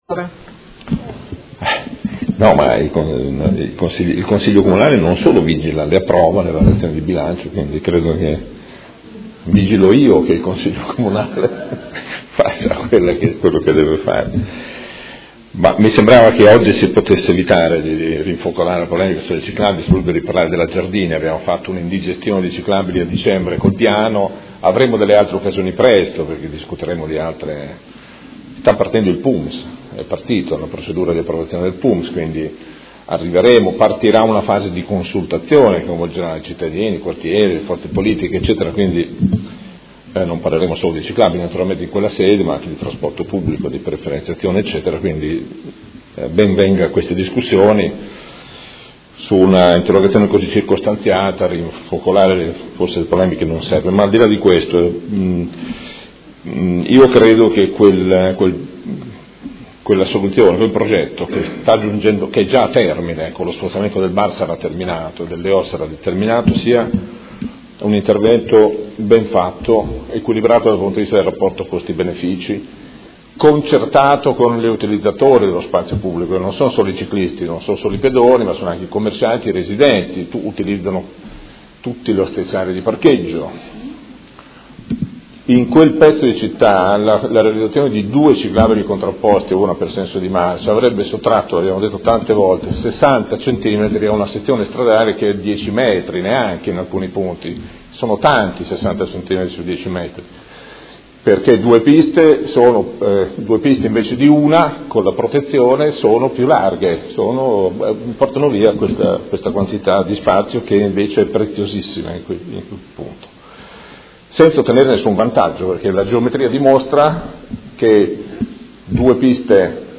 Seduta del 11/05/2015 Conclusioni a dibattito. Interrogazione dei Consiglieri Arletti, Carpentieri e Fasano (PD) avente per oggetto: Ciclabile su Via Emilia Est – quali i tempi per dare continuità al percorso.